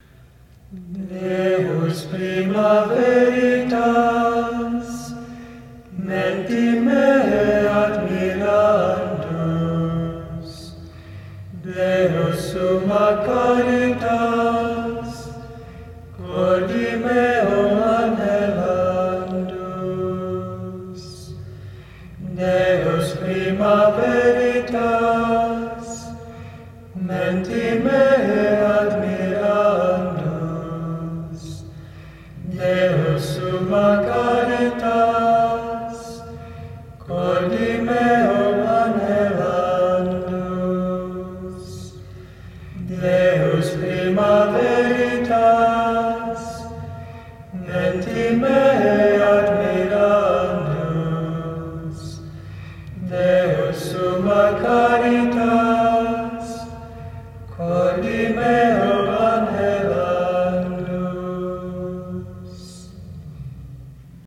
has been singing
without accompaniment